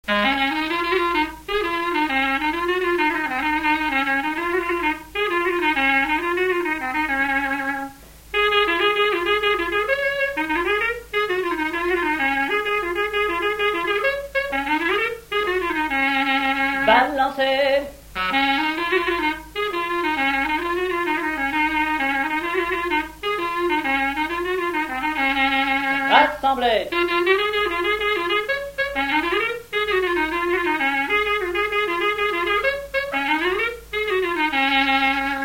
Résumé instrumental
danse : branle : avant-deux
Pièce musicale inédite